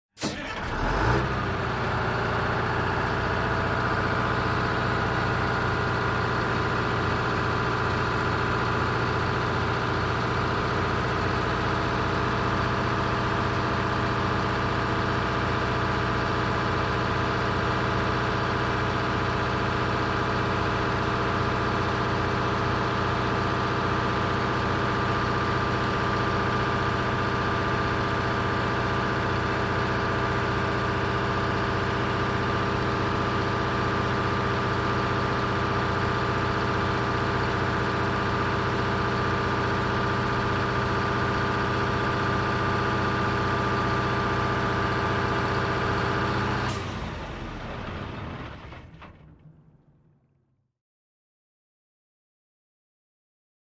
Start, Bus | Sneak On The Lot
Bus Start And Idle Medium Close Up